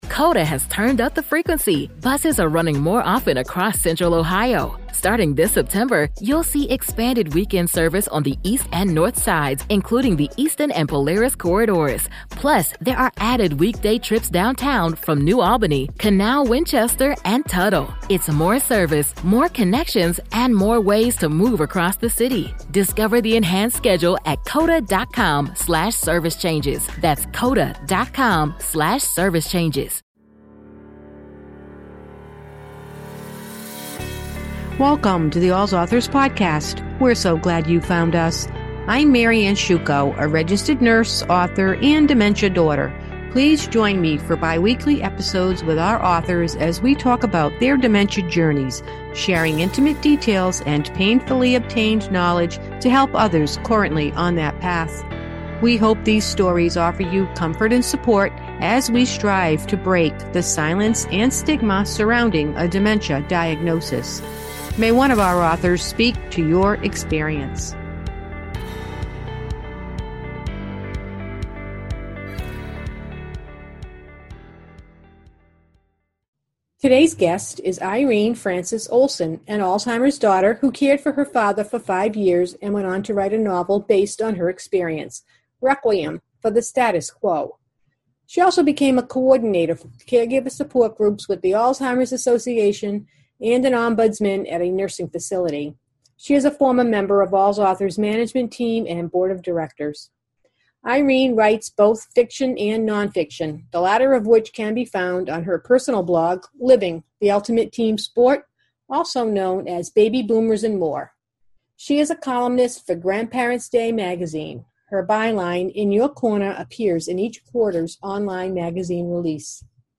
In each episode, we interview one of our authors who may have written a memoir, caregiver guide, novel, children’s book, activity guide, poetry book, or blog.